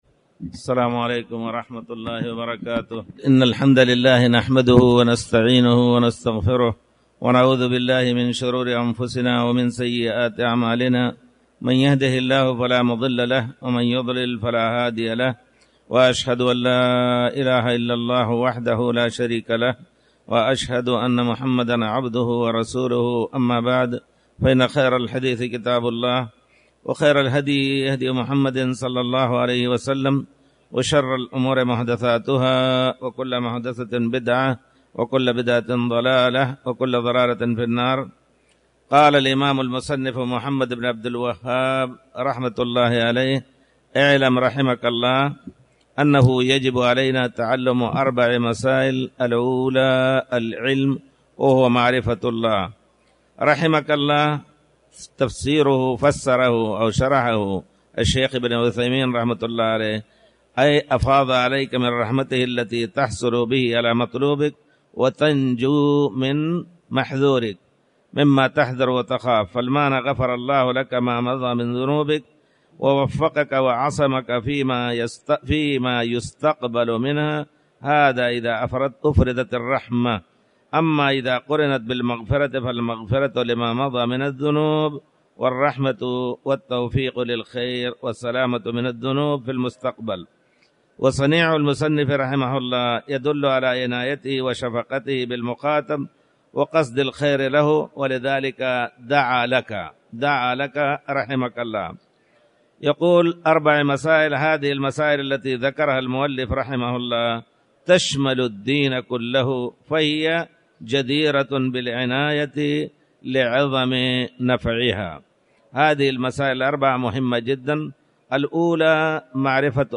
تاريخ النشر ٢٢ رمضان ١٤٣٩ هـ المكان: المسجد الحرام الشيخ